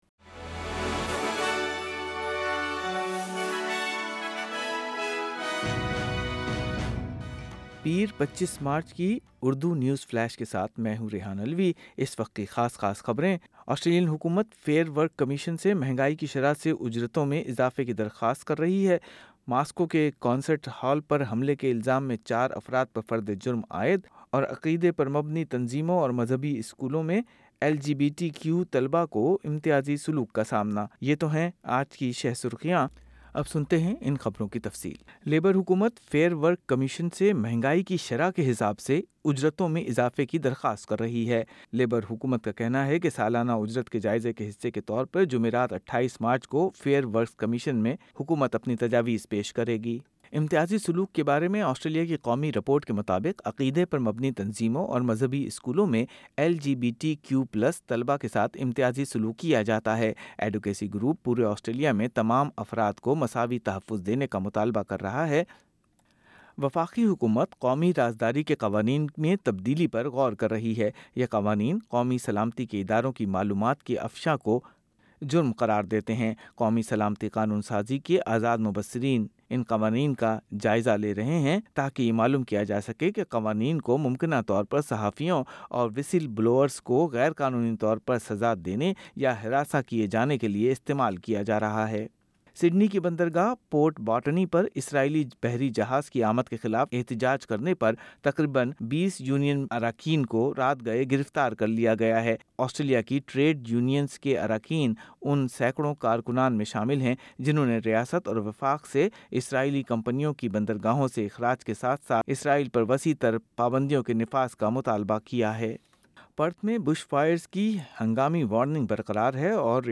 نیوز فلیش: 25 مارچ 2024:ماسکو کے ایک کانسرٹ ہال پر حملے کے الزام میں چار افراد پر فردِ جرم عائد